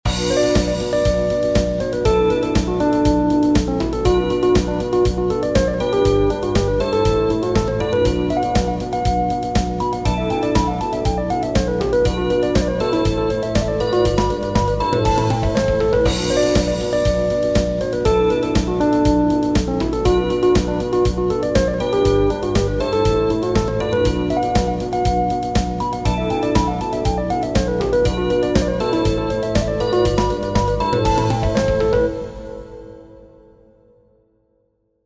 Demo of 7edo
7edo_groove.mp3